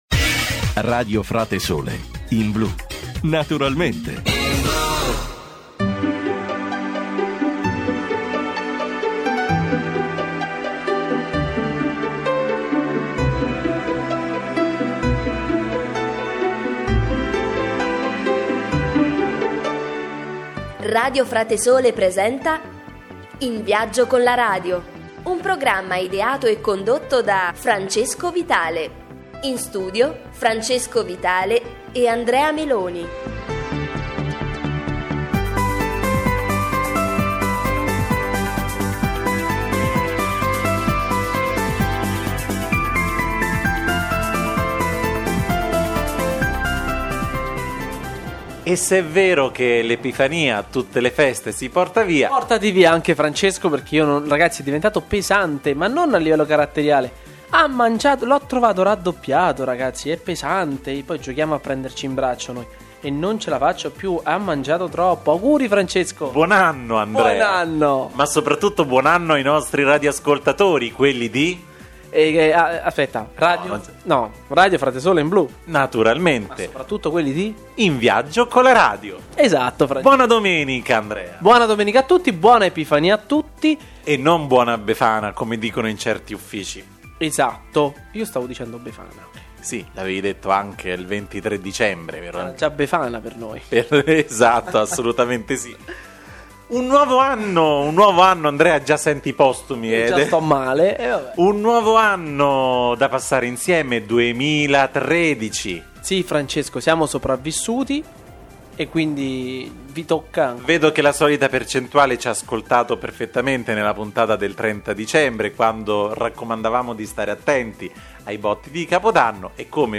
Vi faremo conoscere tre re speciali con i loro doni ancora più speciali che lasceranno virtualmente vicino la culla. E non mancheranno le rubriche, le chiacchiere, la musica e intrattenimento.